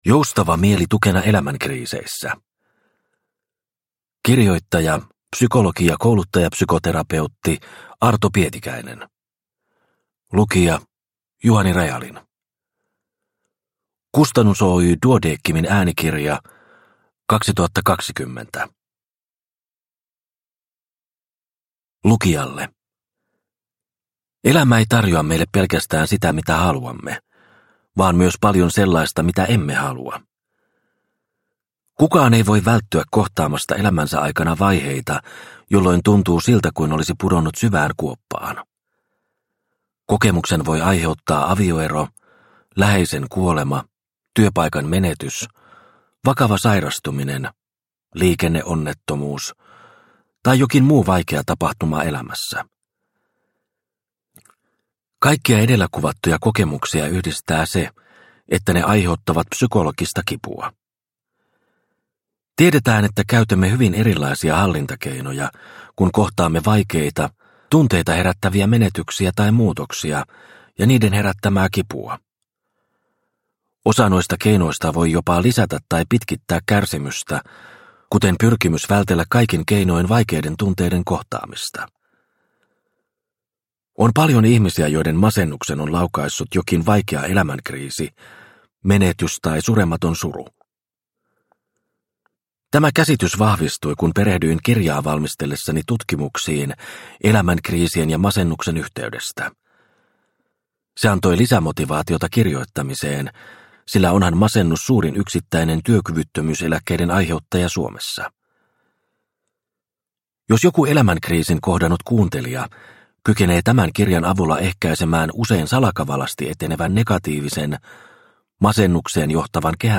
Joustava mieli tukena elämänkriiseissä – Ljudbok – Laddas ner